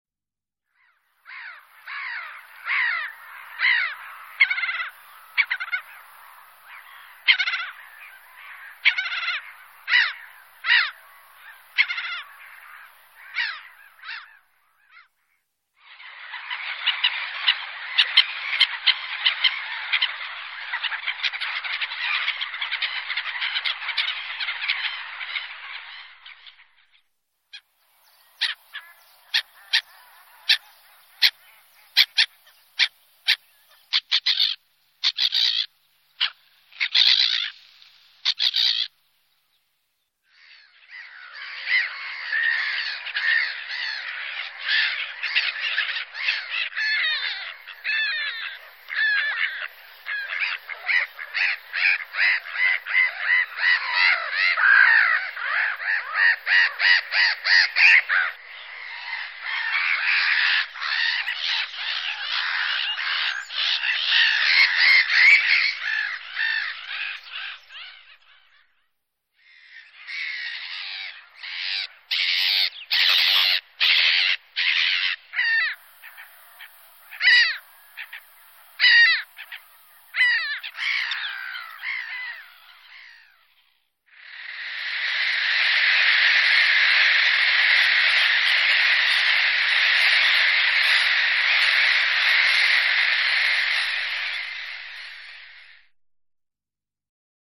racek chechtavý (Chroicocephalus ridibundus)
Hlas Racka Chechtavého
Frymburk – Lipno
Pořízeno při projíždění kolem Lipna.
Racek chechtavý patří k opravdu hlasitým dlouhokřídlým ptákům.
Nejčastěji u něj můžeme zaslechnout chraptivé „krrrjéé“, „kik“ nebo „kikikik“, kterým se ozývá při hledání potravy.
racek-chechtavy--chroicocephalus-ridibundus-.mp3